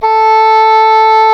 WND OBOE A4.wav